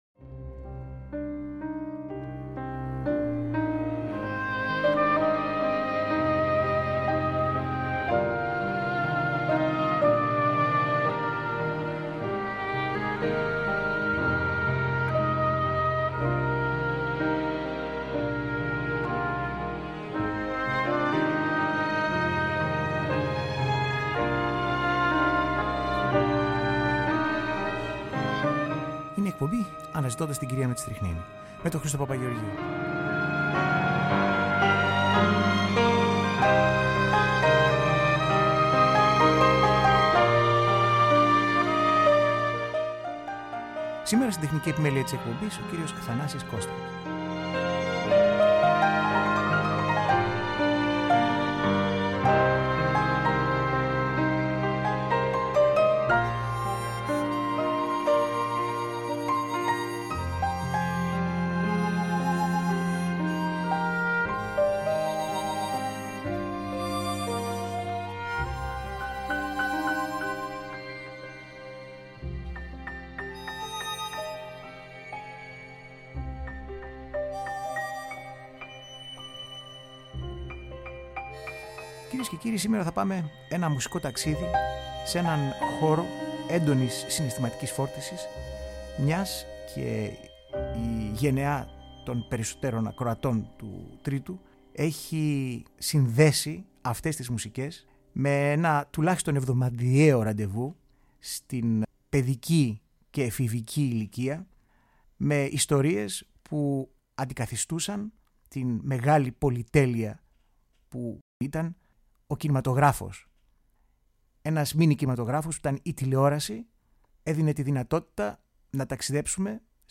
Η πρώτη από τέσσερις αφιερωματικές εκπομπές σε αξέχαστες μουσικές τίτλων εκπομπών και σειρών που αγαπήσαμε στα παιδικά μας χρόνια που στο άκουσμά τους συνεχίζουν να φέρουν το στίγμα και την ένταση των πρώτων μας εμπειριών της χρυσής εποχής του τηλεοπτικού μέσου. Μια σχεδόν ατελείωτη παρέλαση από μουσικές μνήμες που ανακαλούν αισθήματα αναπάντεχης ποικιλίας και περιεχομένου που ξαφνιάζουν ευχάριστα και μας κάνουμε να νοσταλγούμε ίσως κάποιες περιόδους μιας άλλης νιότης.